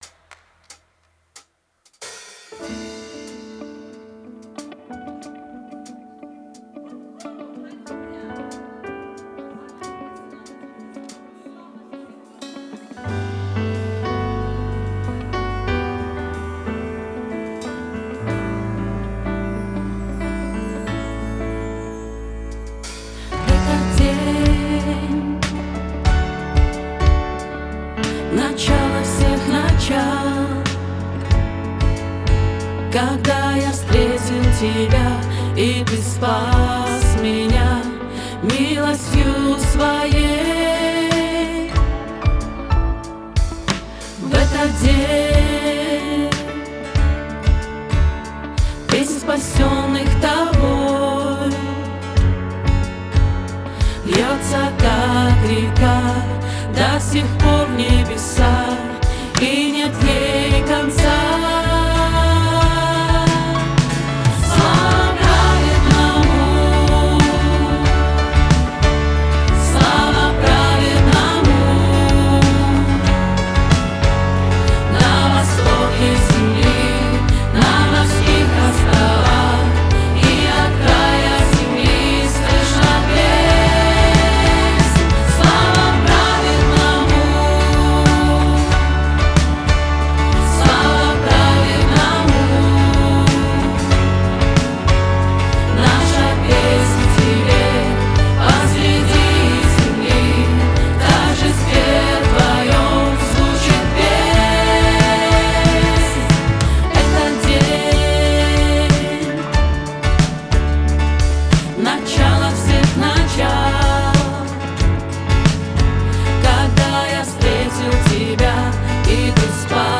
275 просмотров 7 прослушиваний 0 скачиваний BPM: 93